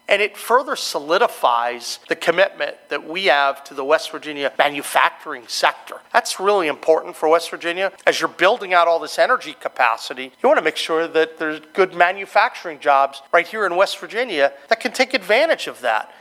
Morrissey said the expansion complements the state’s energy expansion…